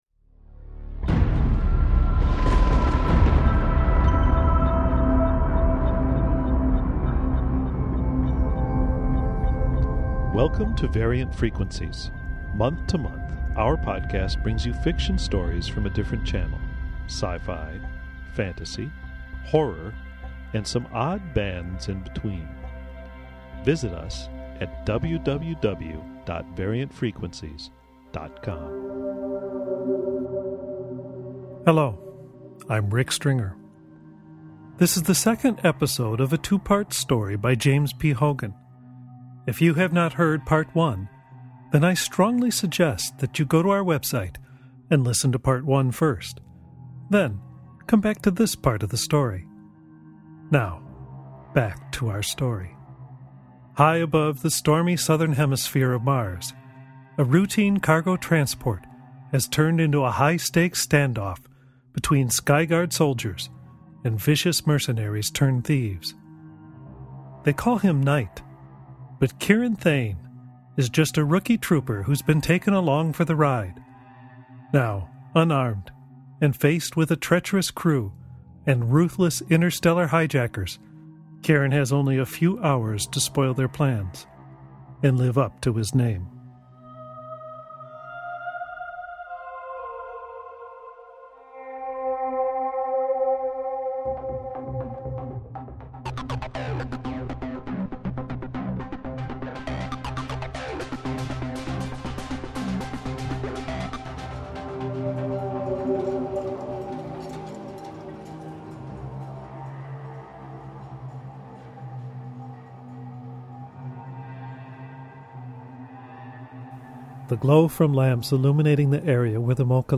Audio Fiction